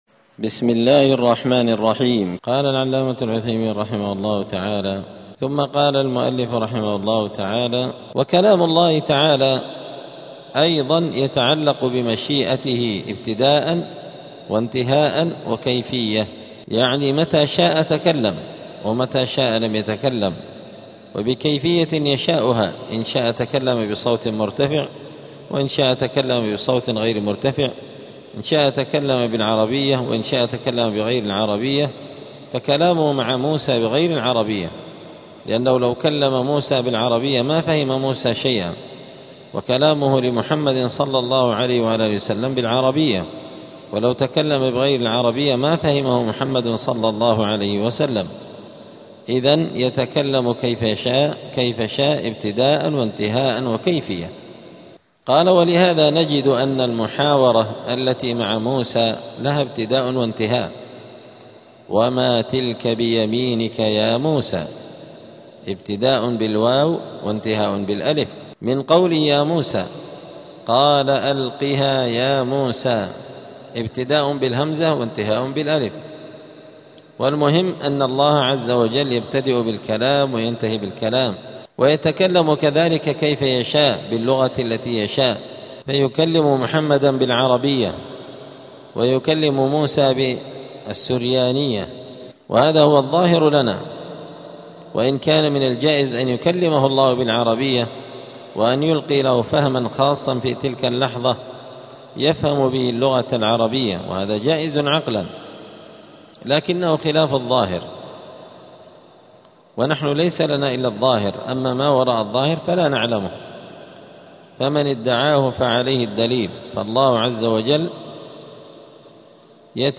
دار الحديث السلفية بمسجد الفرقان قشن المهرة اليمن
34الدرس-الرابع-والثلاثون-من-شرح-العقيدة-السفارينية.mp3